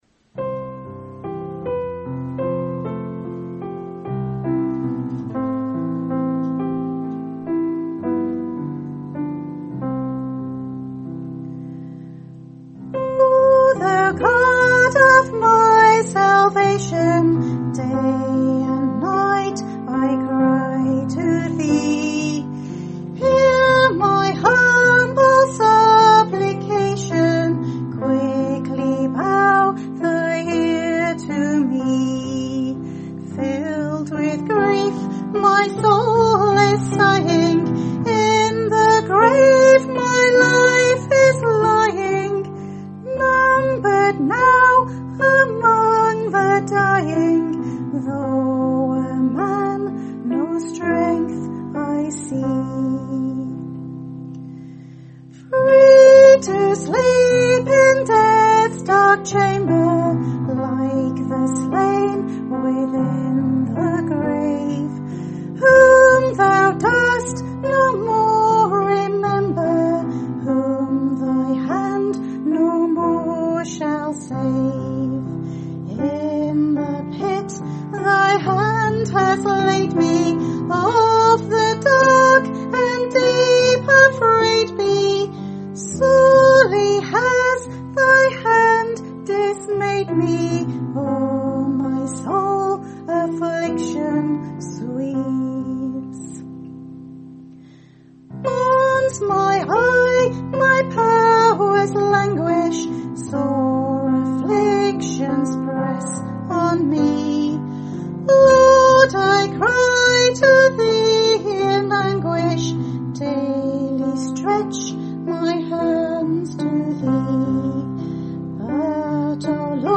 Sermonette
given Wales 9 Jun 2019 - played Wales UK 8 Apr 2026